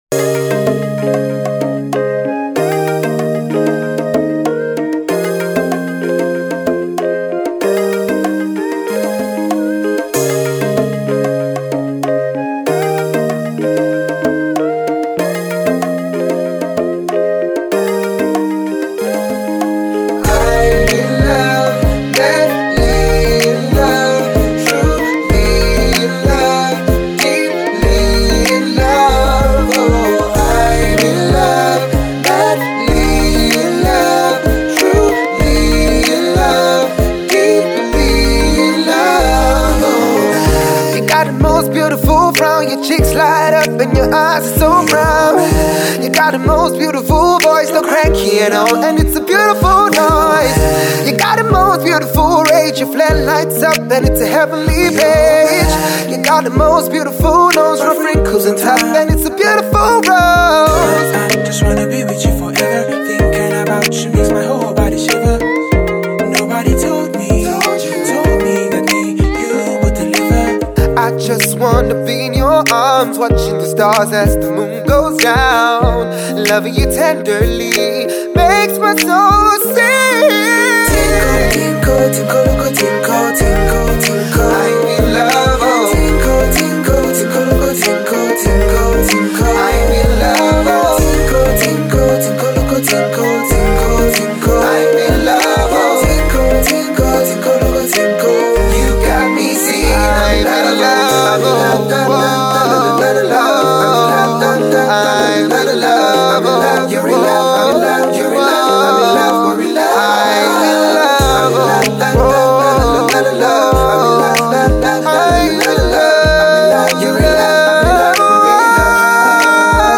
catchy new love jingle